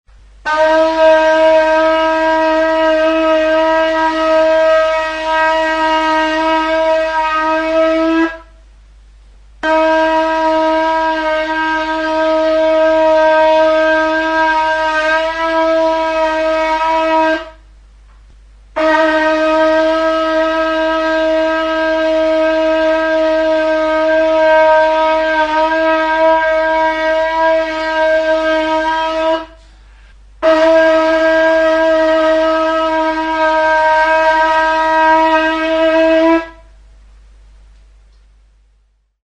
Aerófonos -> Vibración labios (trompeta) -> Naturales (con y sin agujeros)
Grabado con este instrumento.
NAFIR
Hiru zatiz osaturiko zulorik gabeko metalezko tronpeta da.